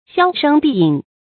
銷聲避影 注音： ㄒㄧㄠ ㄕㄥ ㄅㄧˋ ㄧㄥˇ 讀音讀法： 意思解釋： 猶言銷聲匿跡。